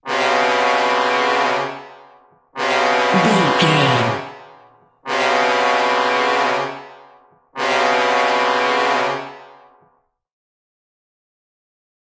Aeolian/Minor
C#
scary
tension
ominous
dark
suspense
eerie
brass
horror
synths
atmospheres